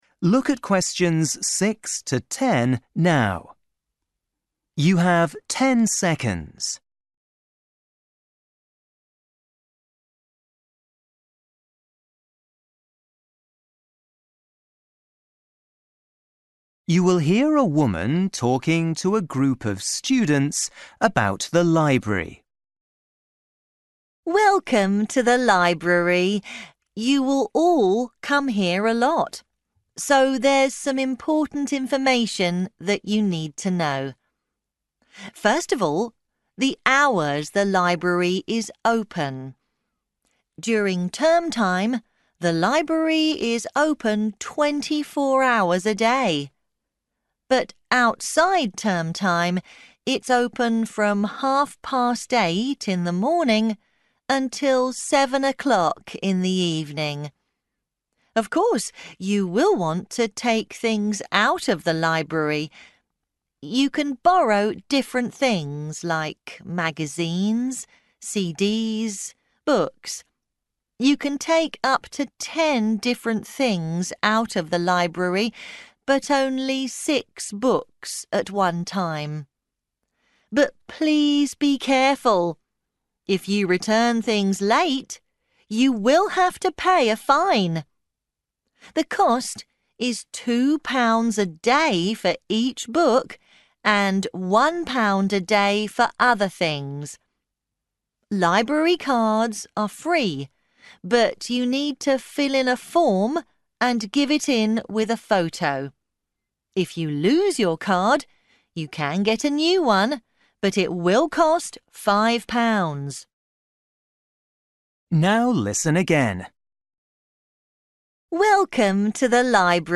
You will hear a woman talking to a group of students about the library.